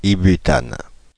Ääntäminen
Synonyymit 2-méthylpropane isobutane triméthylméthane Ääntäminen France (Paris): IPA: /izo.by.tan/ Haettu sana löytyi näillä lähdekielillä: ranska Käännöksiä ei löytynyt valitulle kohdekielelle.